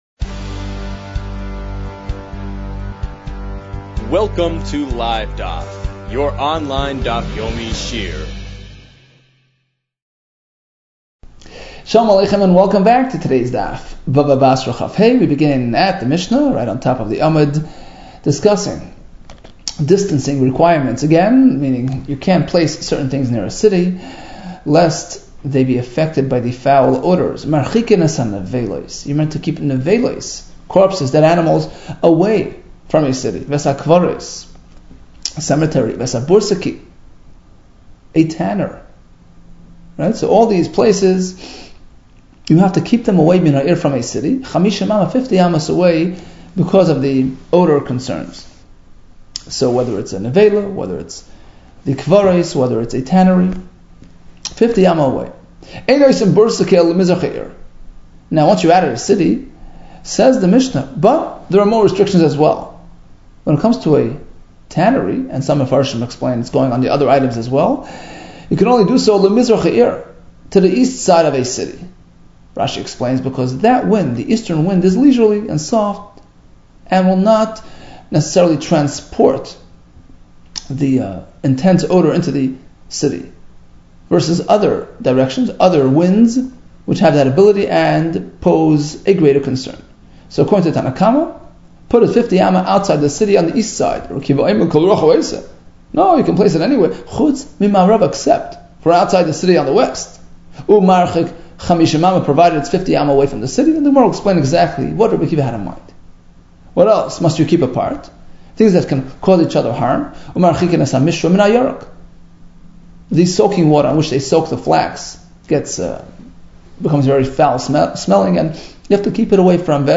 Bava Basra 25 - בבא בתרא כה | Daf Yomi Online Shiur | Livedaf